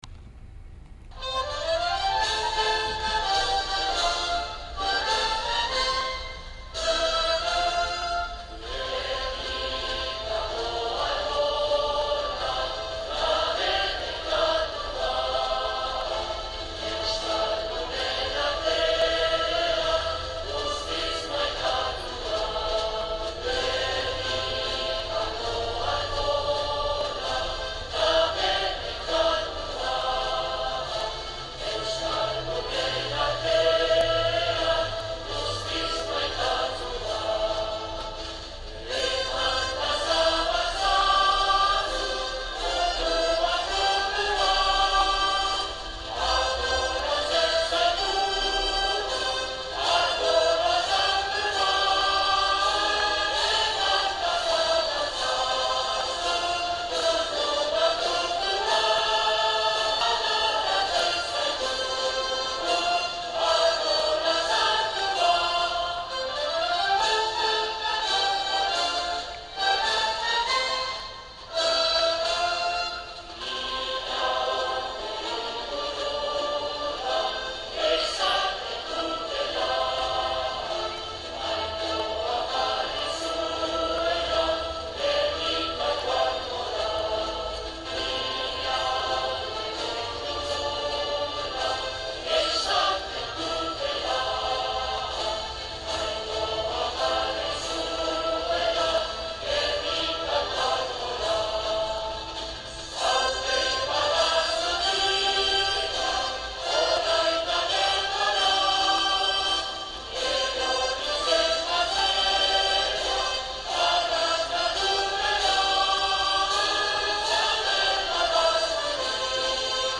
Gernikako arbola (trad. «El árbol de Guernica») es también el título de una canción (en forma de zortziko) escrita en Madrid por el bardo vasco José María Iparraguirre, música de Juan María Blas de Altuna y Mascarua, concertista de órgano y primer organista del órgano romántico Cavaille~Coll de la basílica de Lequeitio, en homenaje al árbol y los fueros vascos. La canción es un himno no oficial para los vascos.